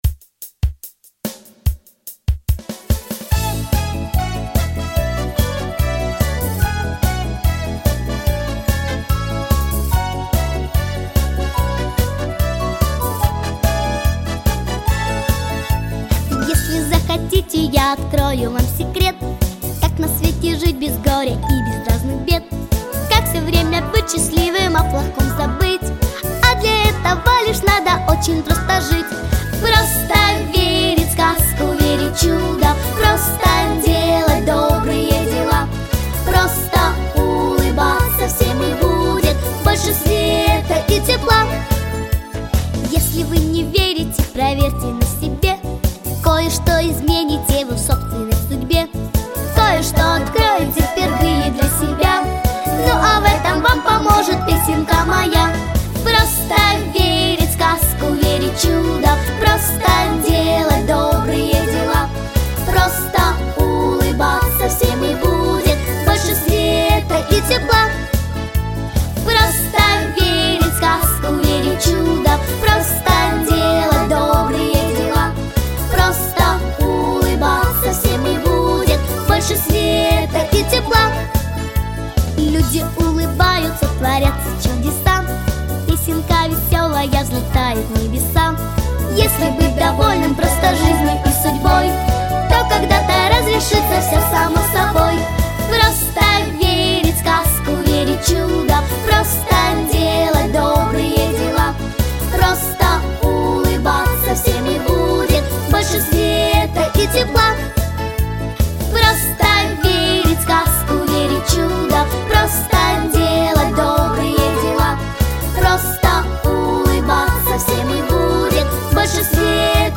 ГлавнаяПесниСовременные детские песни